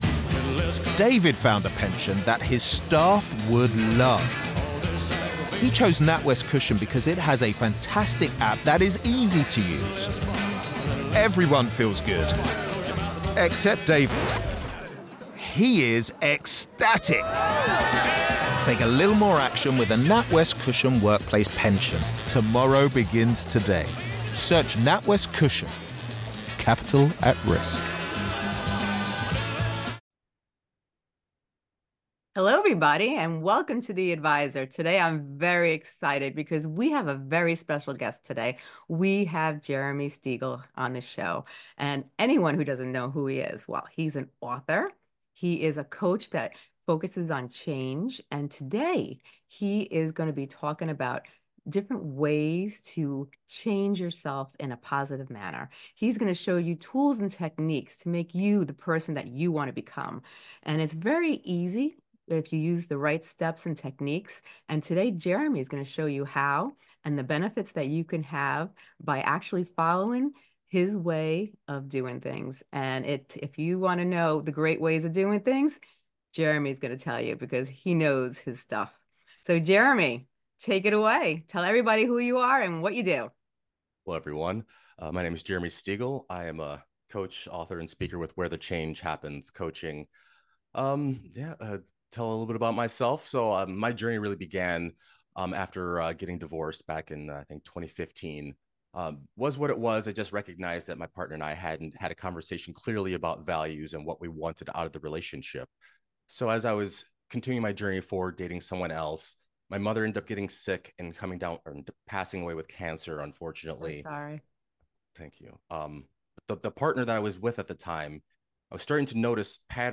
The podcast featured a conversation between two speakers discussing personal development, self-improvement, and the journey of transformation.